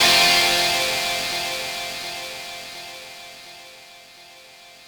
ChordF.wav